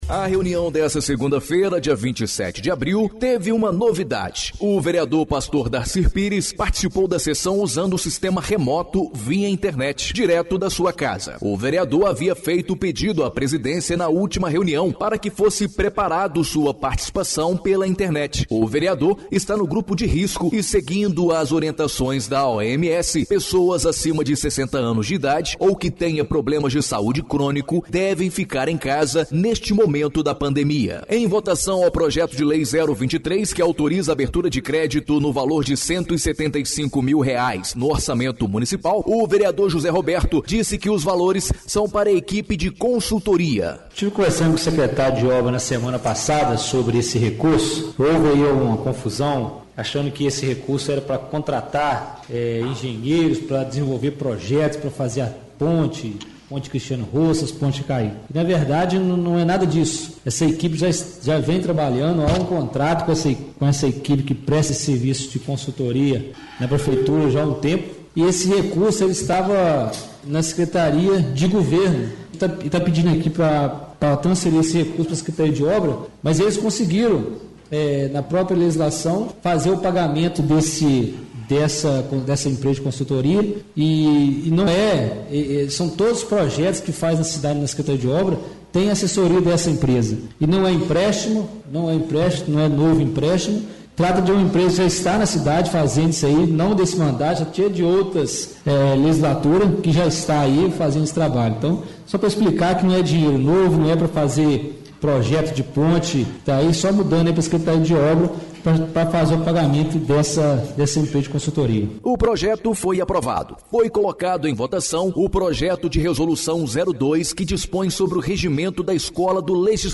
Informativo exibido na Rádio Educadora AM/FM Ubá-MG